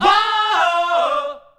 WAAOOHOO.wav